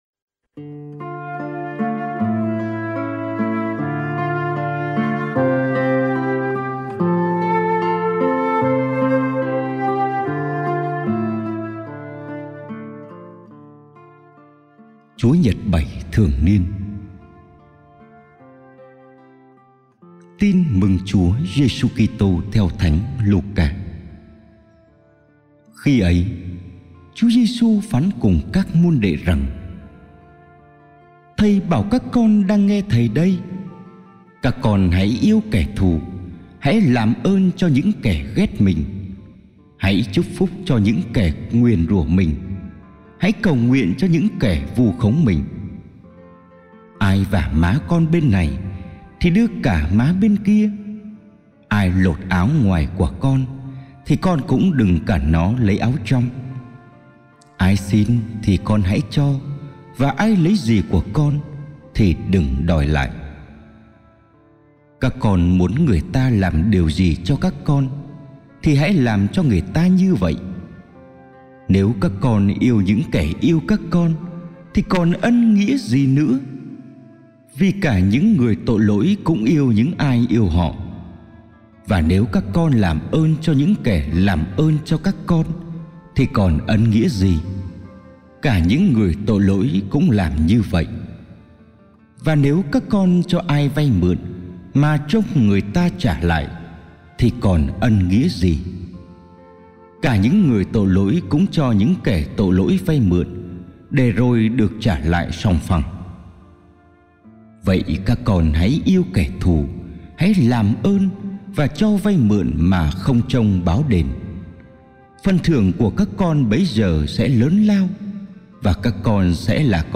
Bài giảng lễ Chúa nhật 8 TN C - 2022